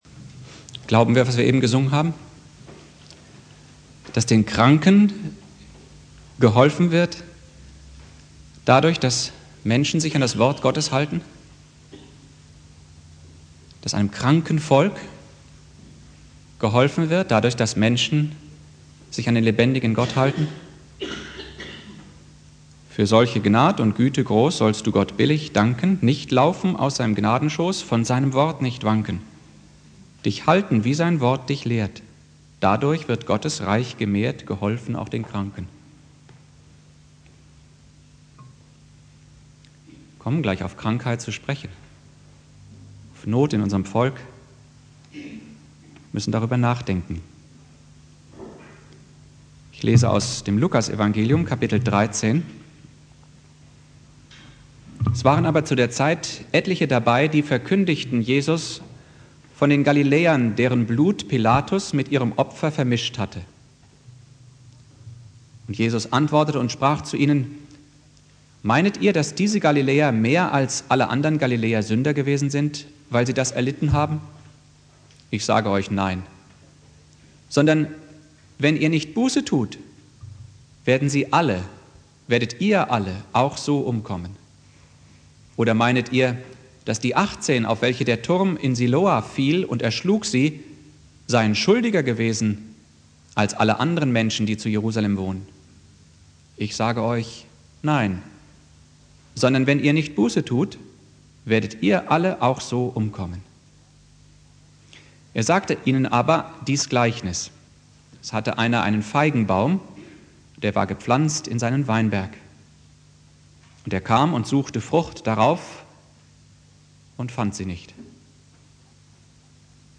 Predigt
Buß- und Bettag Prediger